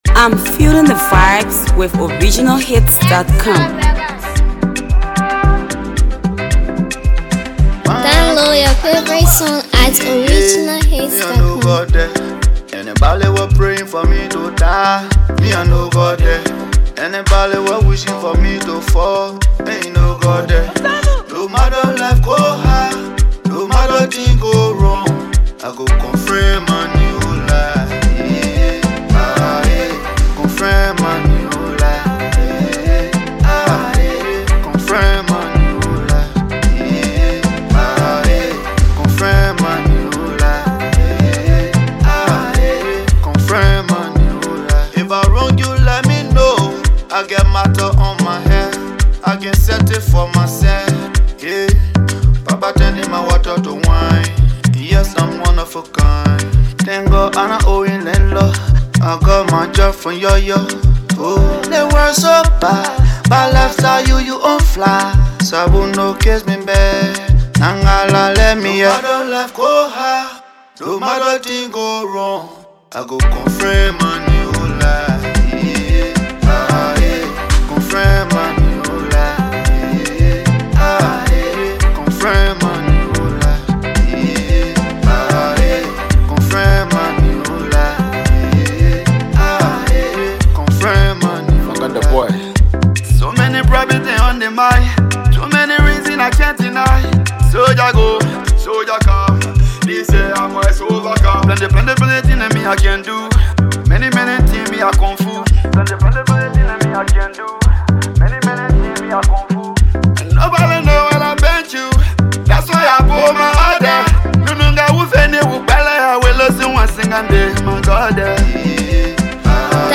AfroAfro PopLATEST PLAYLISTMusic